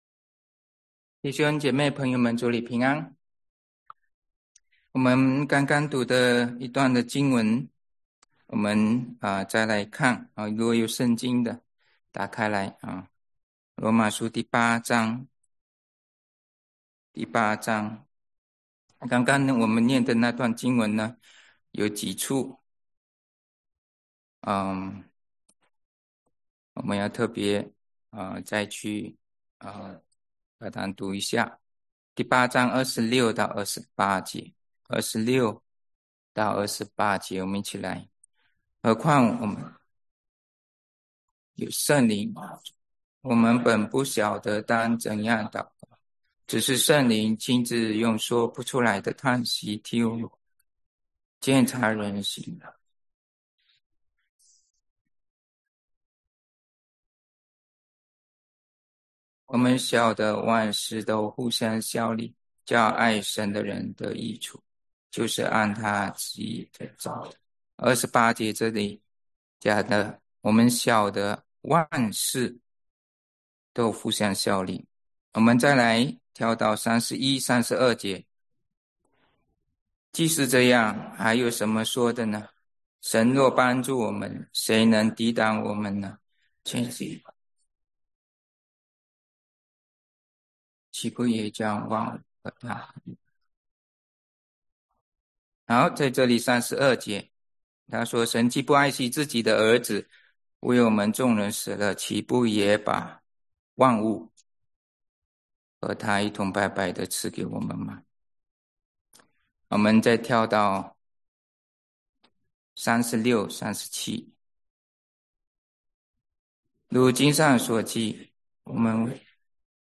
英文講道